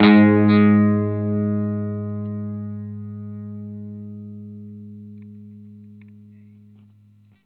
R12 NOTE  GS.wav